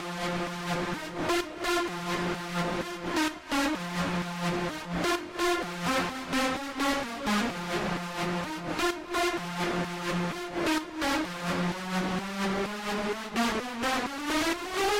宽敞的合成器
描述：这是我为一首EDM歌曲的部分休息所做的循环。
Tag: 120 bpm House Loops Synth Loops 344.57 KB wav Key : Unknown